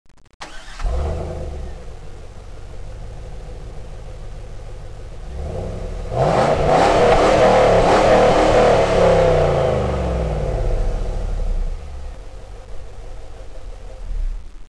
A small idea of the potional of the LS1
Z28_Rev.mp3